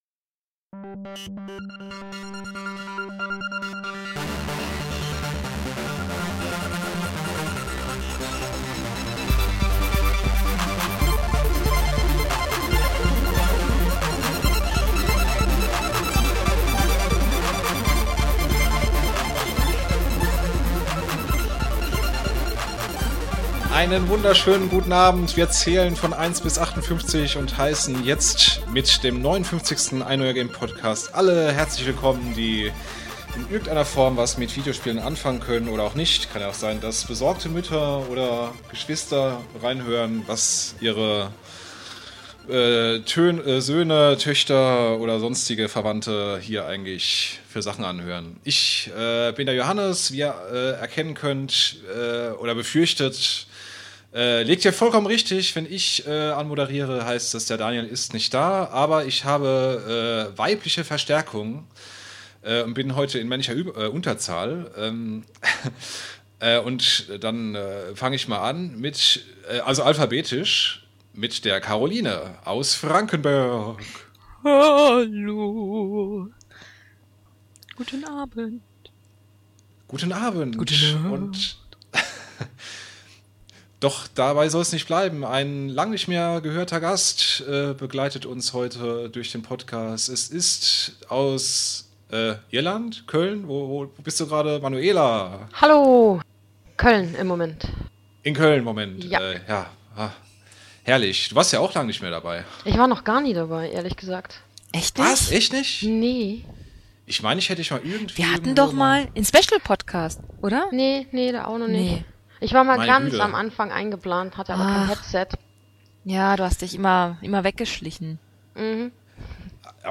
Heute bin ich zur Abwechslung mal der Hahn im Korb, wie es so schön heißt.